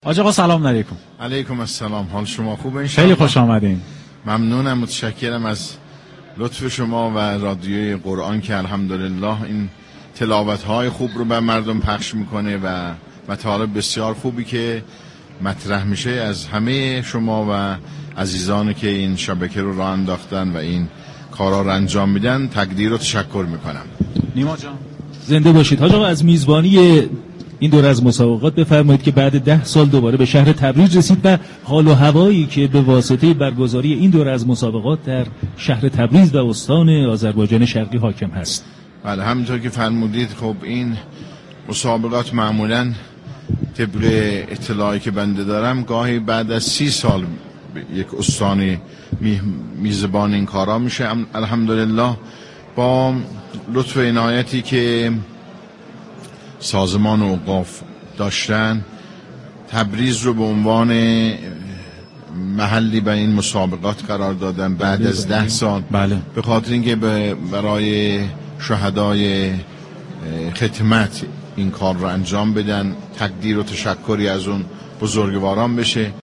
امام جمعه تبریز با حضور در استودیوی رادیو قرآن كه در محل برگزاری مسابقات سراسری قرآن مستقر است، از فعالیت‌های قرآنی این شبكه تشكر و قدردانی كرد.
امام جمعه تبریز در گفت‌وگویی كه به‌صورت زنده و مستقیم از شبكه رادیویی قرآن پخش می‌شد، گفت: از رادیو قرآن كه الحمدلله این تلاوت‌های خوب را پخش می‌كنند و مطالب بسیار خوبی كه در این رادیو مطرح می‌شود و همچنین دست‌اندركاران و عزیزانی كه این كارها را انجام می‌دهند، تشكر و قدردانی می‌كنم.